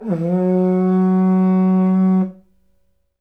Index of /90_sSampleCDs/NorthStar - Global Instruments VOL-1/WND_AfrIvoryHorn/WND_AfrIvoryHorn